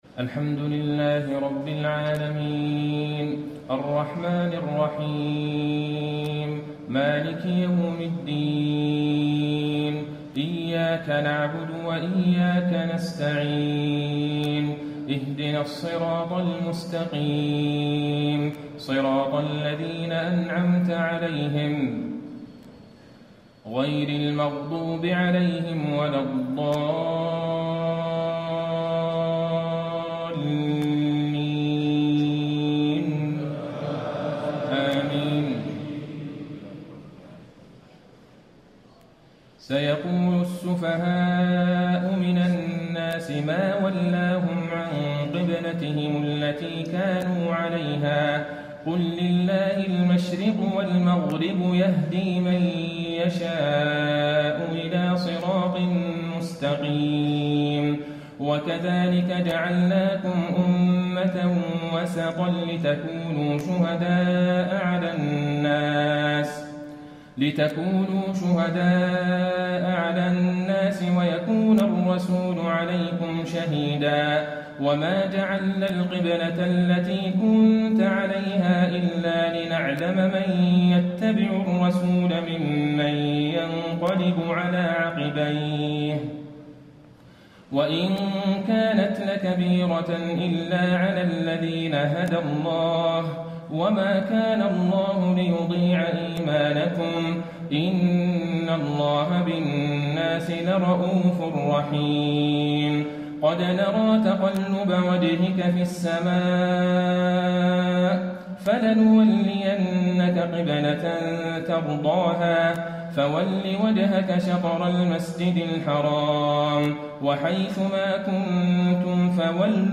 تهجد ليلة 22 رمضان 1435هـ من سورة البقرة (142-218) Tahajjud 22 st night Ramadan 1435H from Surah Al-Baqara > تراويح الحرم النبوي عام 1435 🕌 > التراويح - تلاوات الحرمين